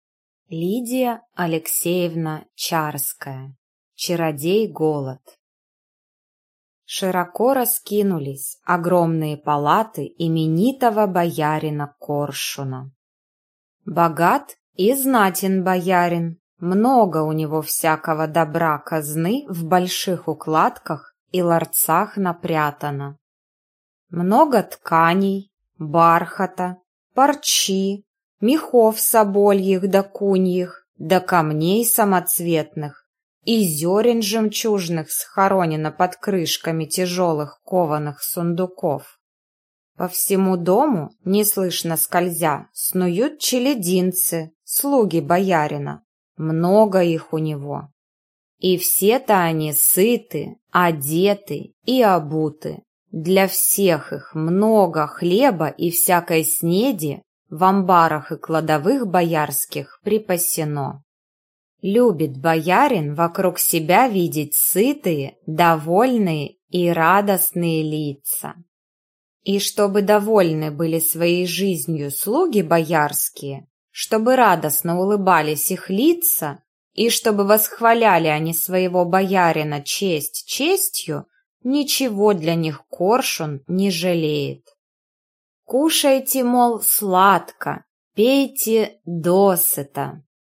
Аудиокнига Чародей Голод | Библиотека аудиокниг